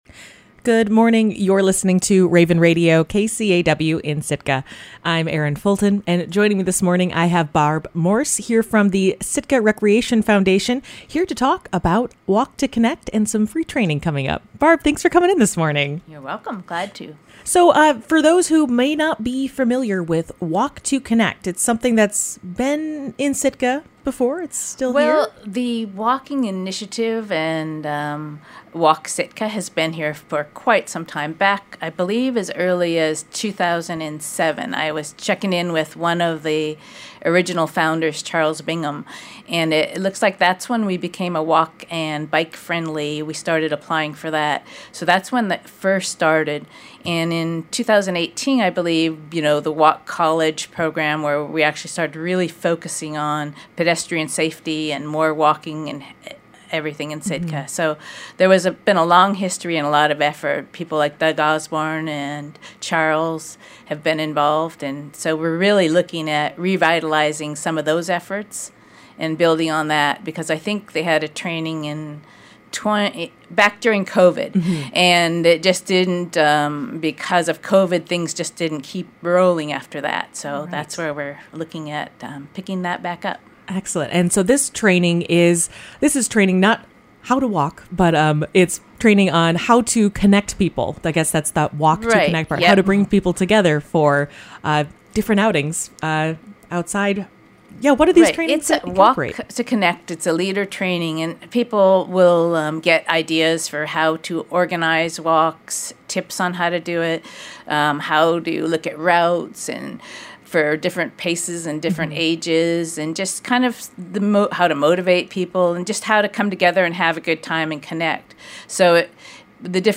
Morning Interview